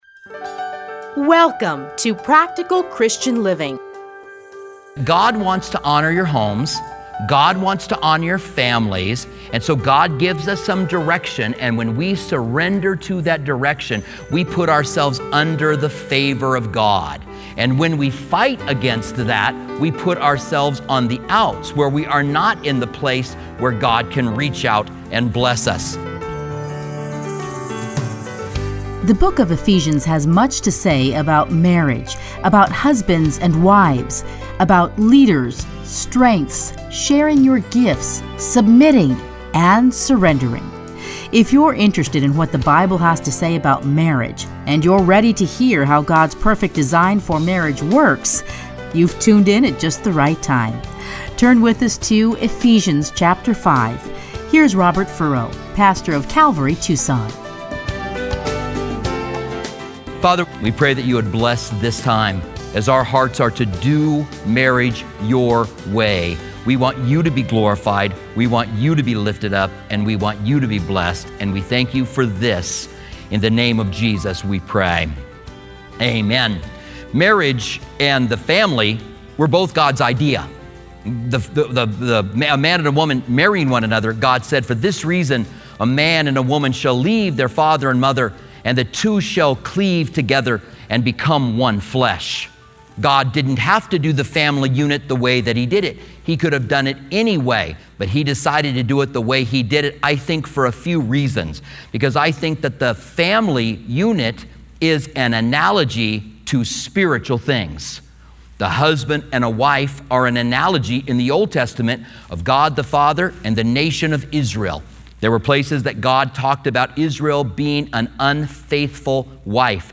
Listen here to his commentary on Ephesians.